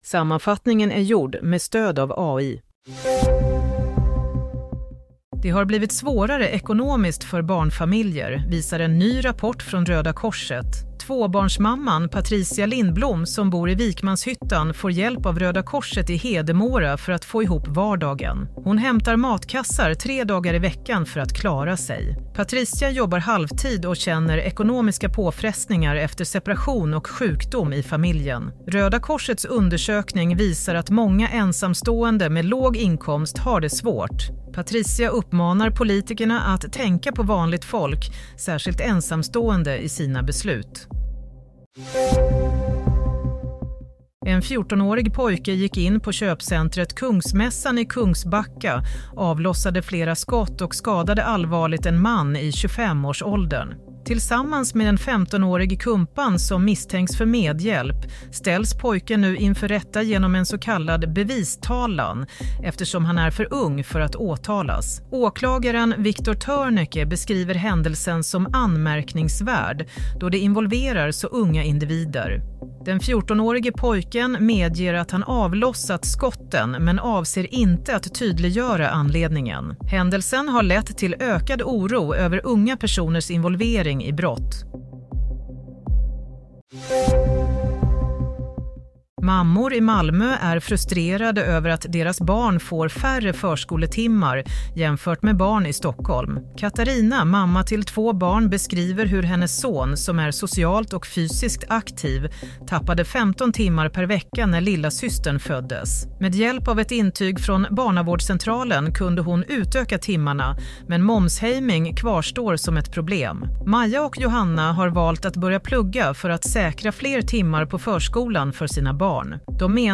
Play - Nyhetssammanfattning – 26 mars 07:00
Sammanfattningen av följande nyheter är gjord med stöd av AI: